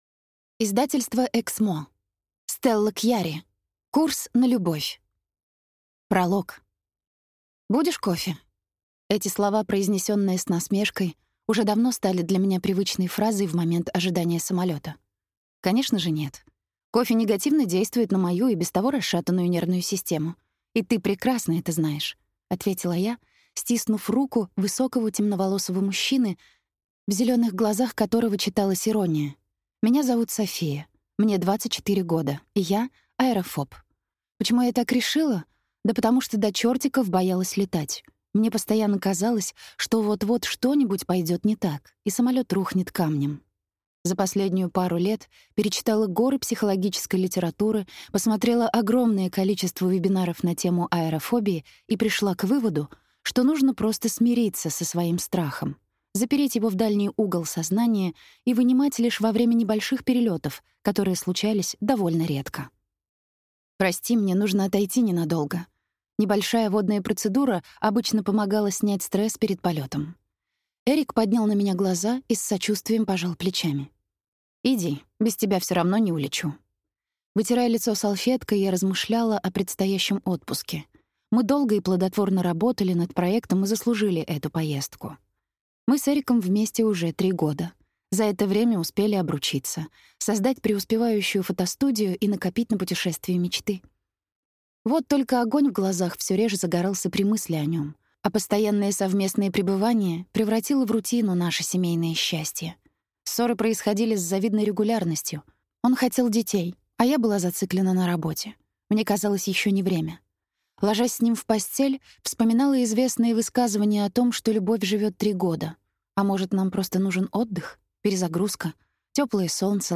Аудиокнига Курс на любовь | Библиотека аудиокниг